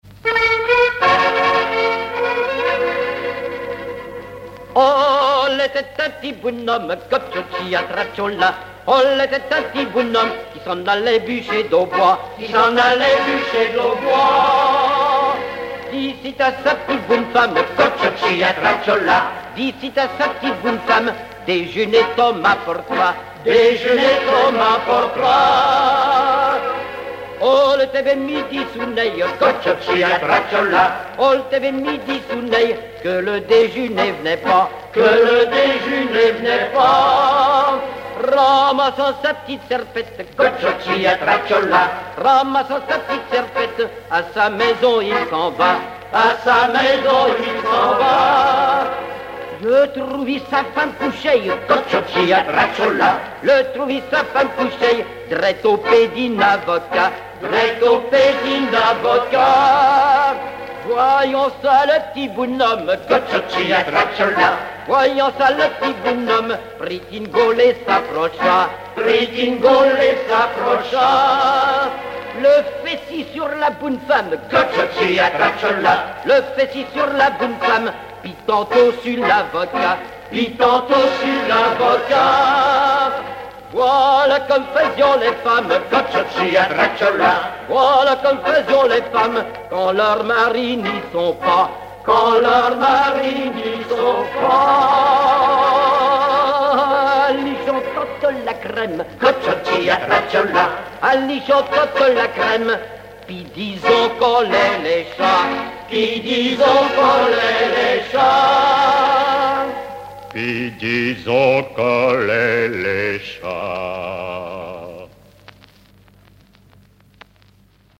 Patois local
Pièce musicale inédite